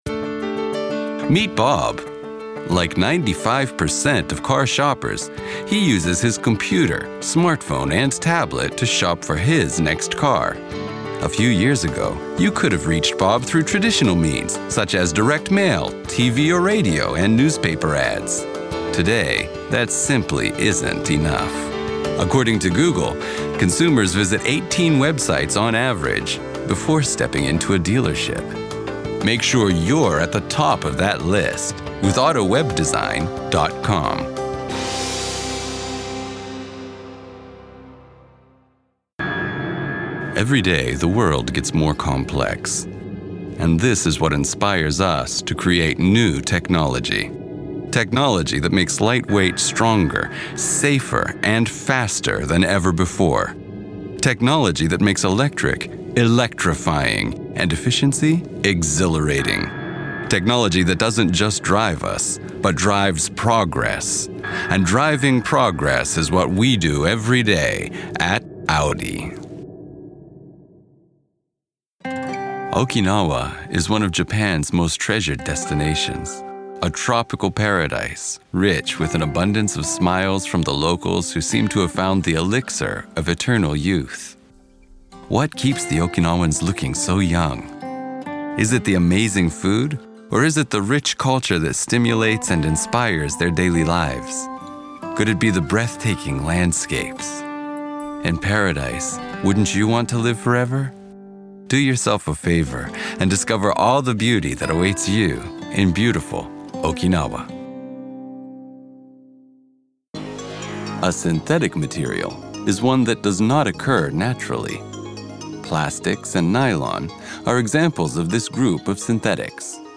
男性
アメリカ英語
narration, stage acting, MC, and voice acting